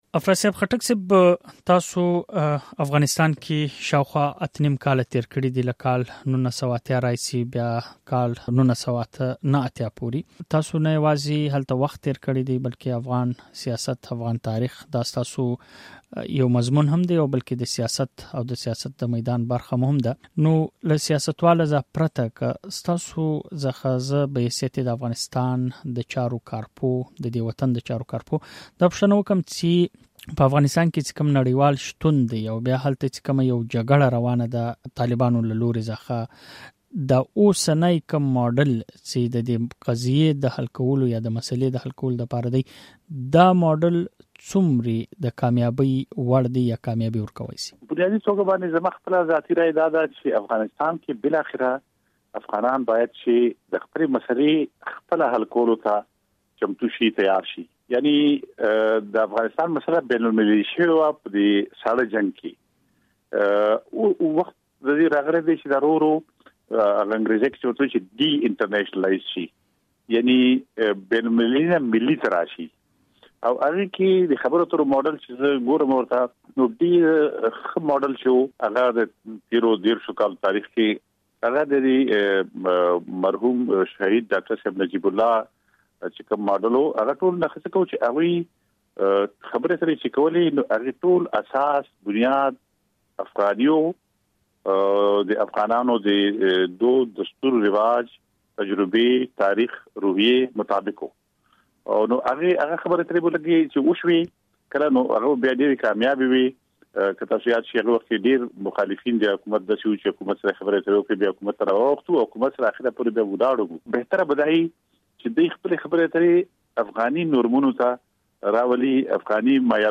پوښتنه دا ده چې ایا د خبرو اترو داسې یو خاص جوړښت یا ماډل یا چوکاټ شته چې تر مخه یې په افغانستان کې تلپاتې سوله راشي؟ په دې اړه مو د عوامي نیشنل ګوند له یوه مشر او سینېټر افراسیاب خټک سره خبرې کړې دي.
مشال راډیو له افراسیاب خټک سره د یوه سیاستوال نه بلکې د افغانستان د چارو د پوه په حیث مرکه کړې ده چې تاسې یې د غږ (ږغ) په ځای کې اورېدای شئ.